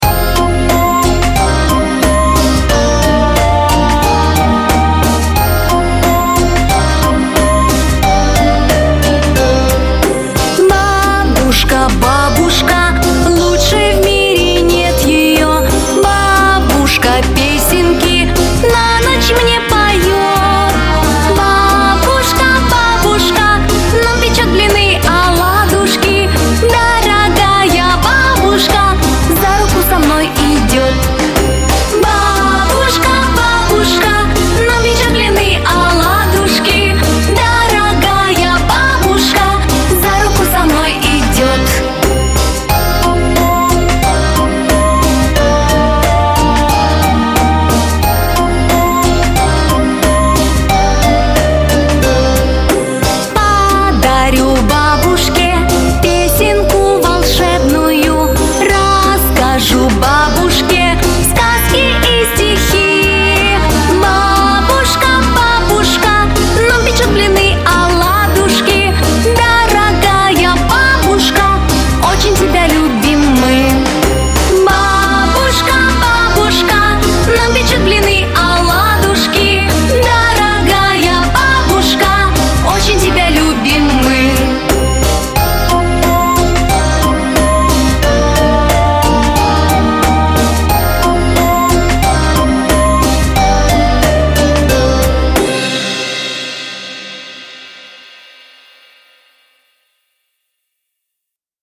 Главная / Песни для детей / Песни про бабушку